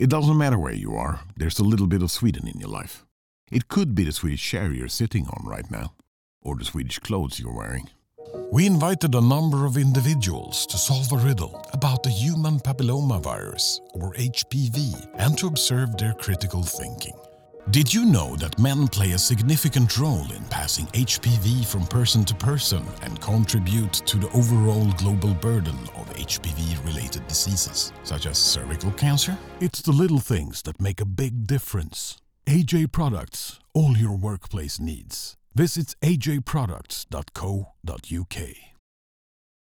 Male
Approachable, Authoritative, Confident, Conversational, Corporate, Deep, Energetic, Engaging, Versatile, Warm
European english with a slight swedish twang
Microphone: Austrian Audio OC18